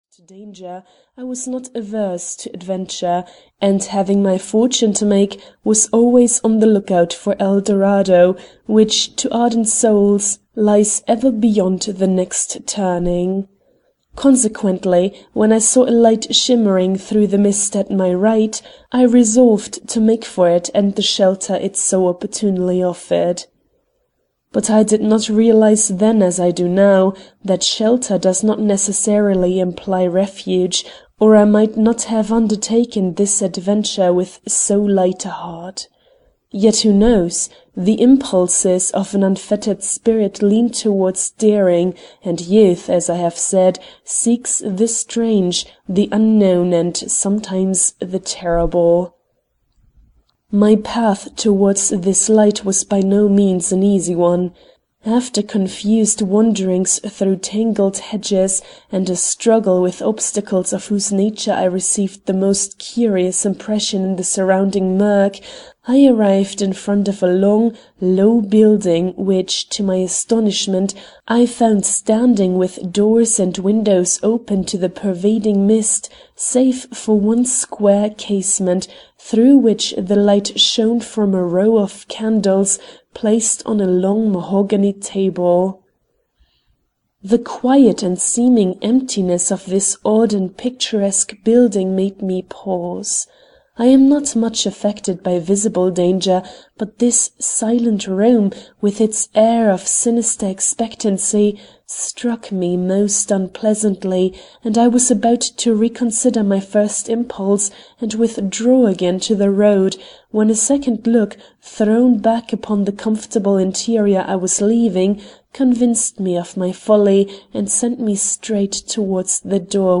The house in the Mist (EN) audiokniha
Ukázka z knihy